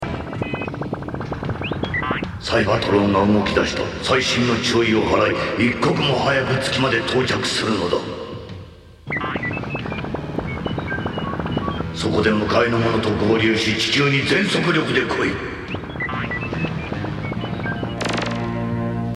you can hear R2-D2 from Star Wars.
R2D2 BlackZarak transformers masterforce.mp3